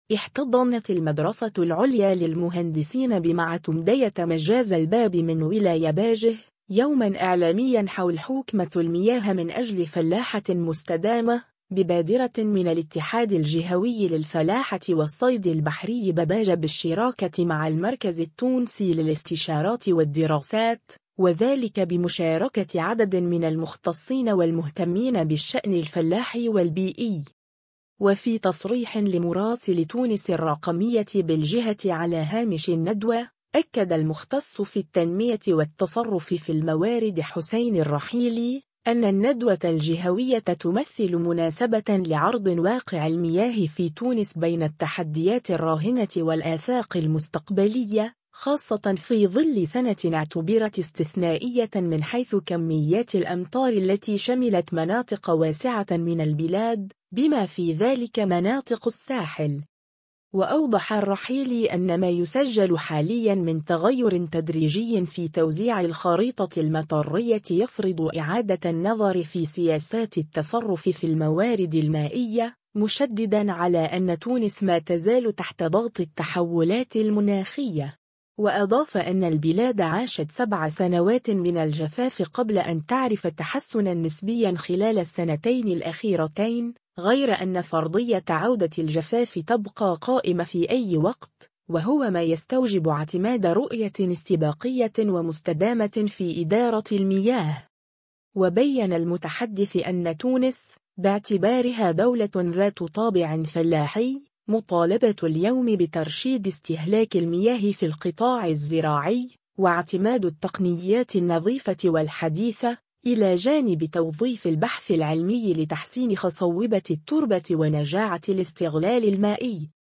احتضنت المدرسة العليا للمهندسين بمعتمدية مجاز الباب من ولاية باجة، يوماً إعلامياً حول “حوكمة المياه من أجل فلاحة مستدامة”، ببادرة من الاتحاد الجهوي للفلاحة والصيد البحري بباجة بالشراكة مع المركز التونسي للاستشارات والدراسات، وذلك بمشاركة عدد من المختصين والمهتمين بالشأن الفلاحي والبيئي.